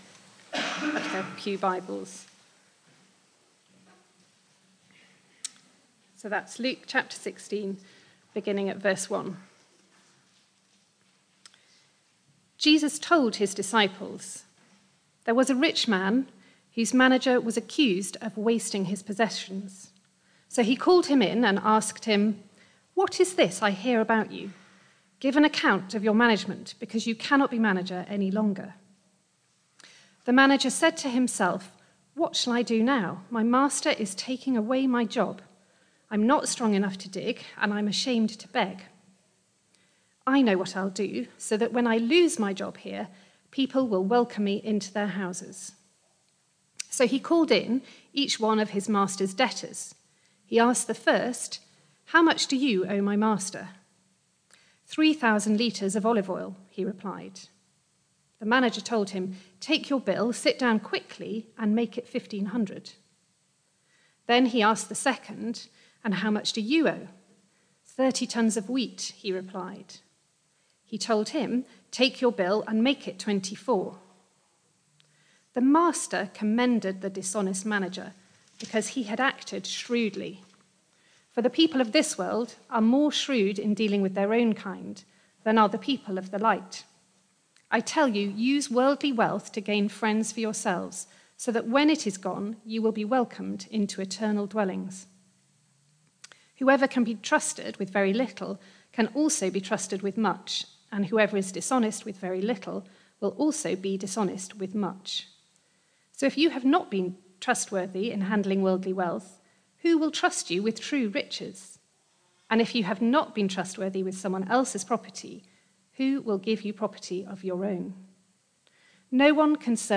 Media for Barkham Morning Service on Sun 02nd Jul 2023 10:00
Passage: Luke 16:1-13 Series: Parables of Jesus Theme: Sermon